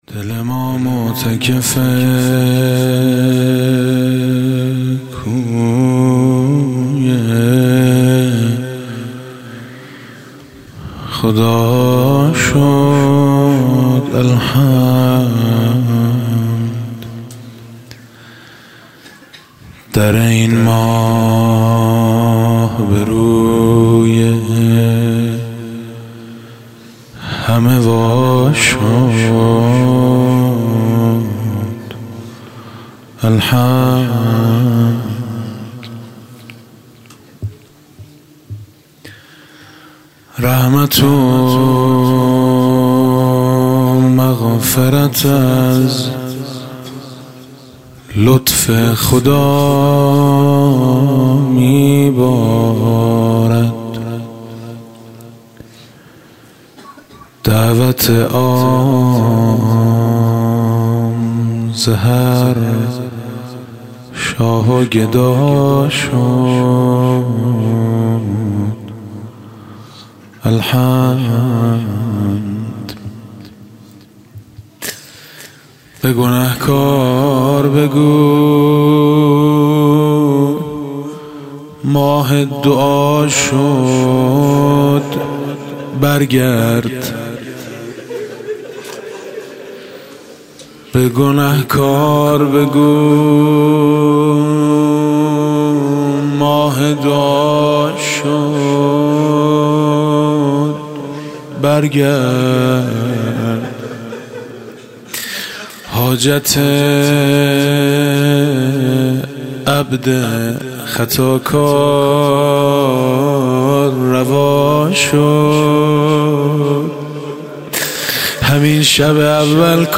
27 اردیبهشت 97 - هیئت میثاق با شهدا - مناجات - آری یک عمر اگر توبه شکستی باز آی